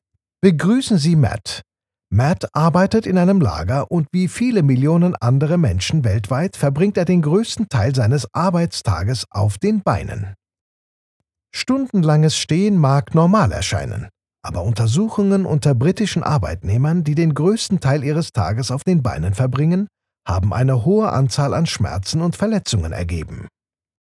Explainer & Whiteboard Video Voice Overs
Adult (30-50)